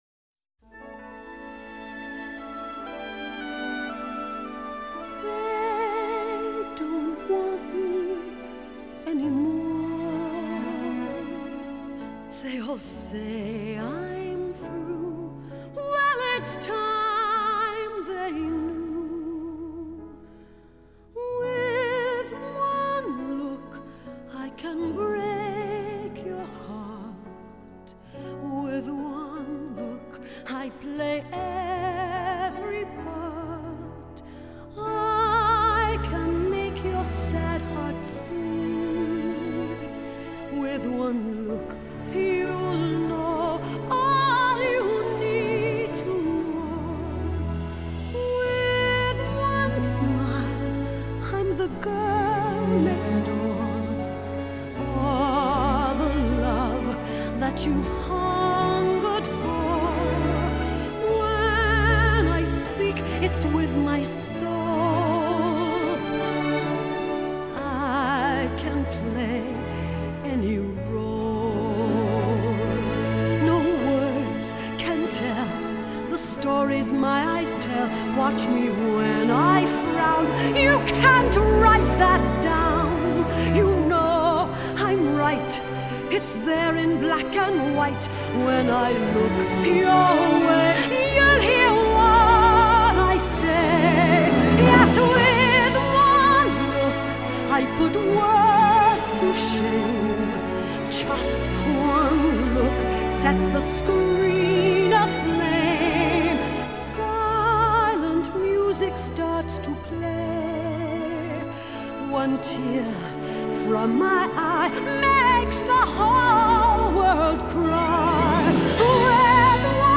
明朗澄澈的录音效果